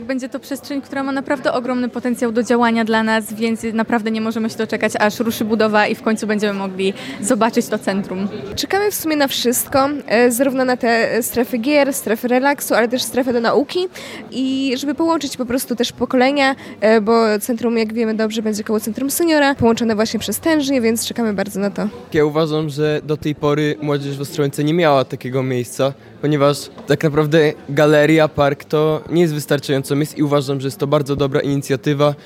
Młodzi mieszkańcy Ostrołęki nie ukrywali zadowolenia, że na terenach po dawnej komendzie powstanie miejsce, w którym będą mogli się integrować.